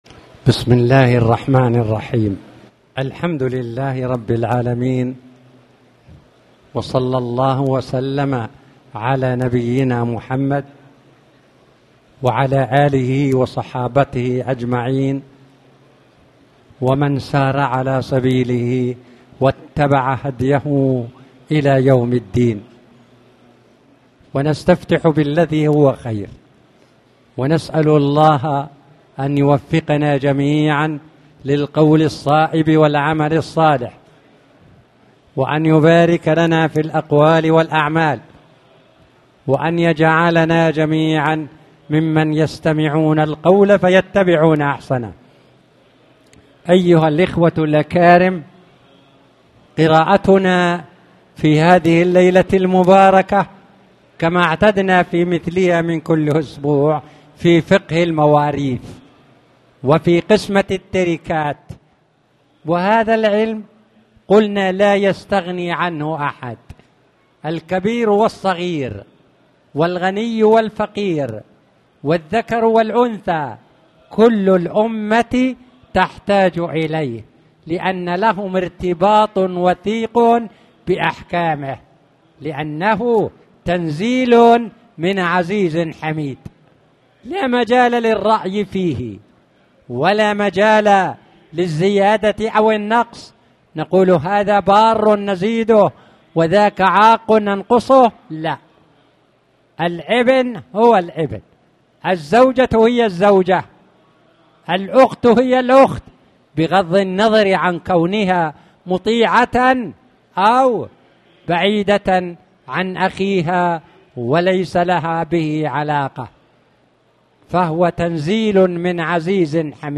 تاريخ النشر ٣ صفر ١٤٣٩ هـ المكان: المسجد الحرام الشيخ